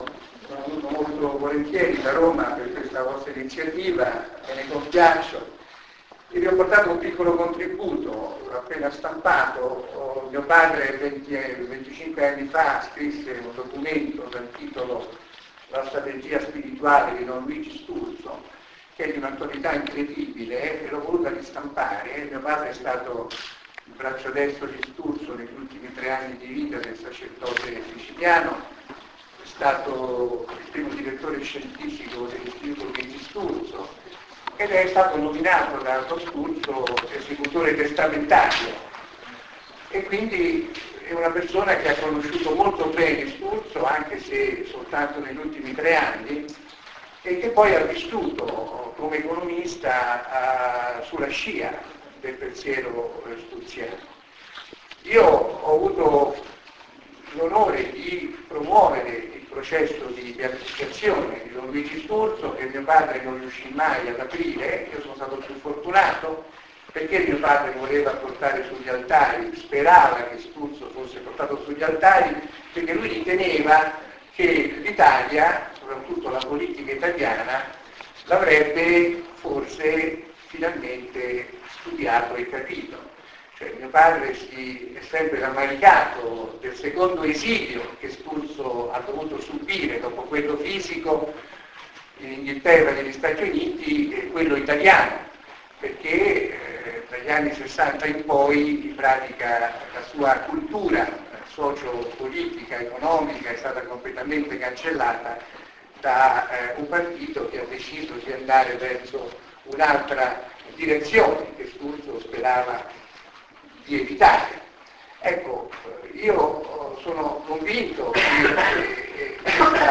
«LUIGI STURZO NELLA CULTURA POLITICA DEL NOVECENTO» (Fondazione Donat-Cattin  Torino, 26 giugno 2009):
Intervento